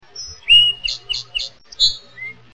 The Diversity of Song Sparrow Songs
All songs were sung by a single individual male Song Sparrow over a period of 2-3 hours. Each song differs in its cadence, phrases (single notes, trills), or sequence of phrases.